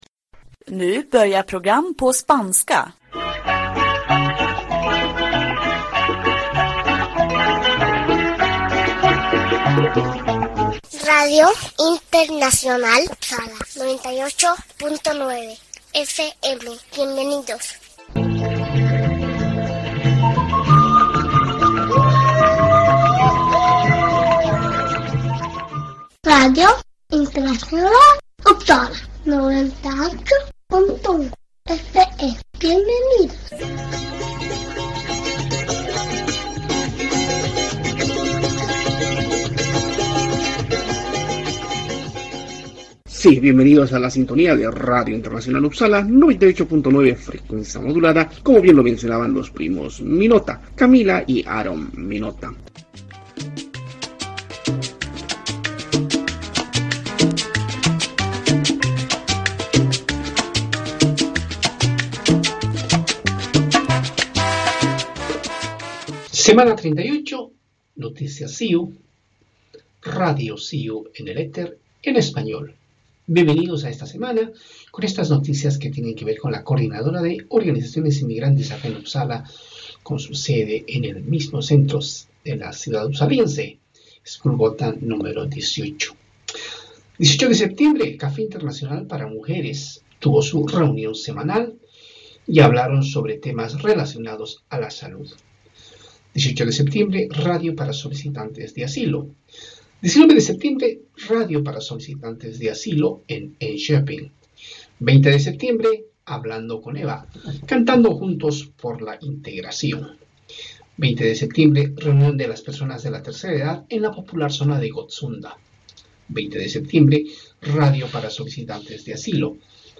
Sí, radio de cercanías en Uppsala se emite domingo a domingo a horas 18:30. La asociación de amistad Suecia-Latinoamérica SANKHAYU está detrás de todo este trabajo informativo,que fundamentalmente enfoca a noticias que tienen que ver con el acontecer de los inmigrantes en Uppsala.